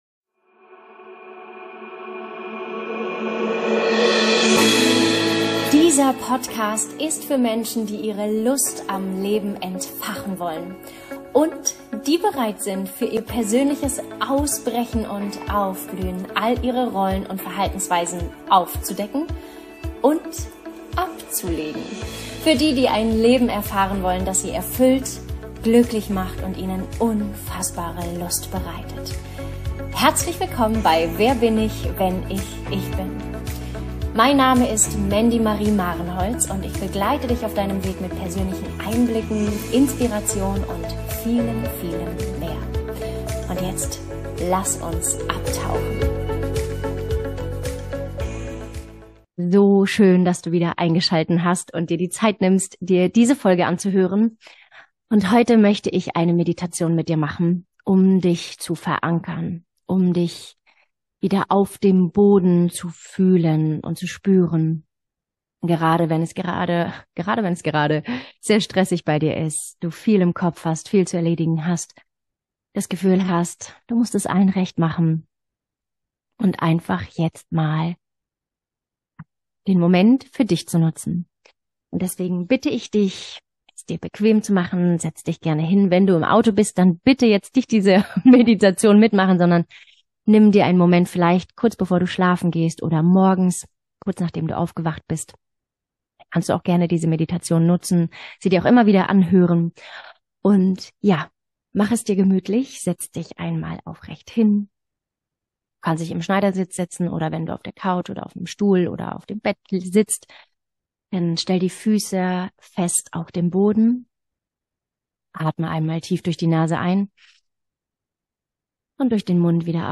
#024 Verwurzelt im Sturm: Eine Meditation zur inneren Stabilität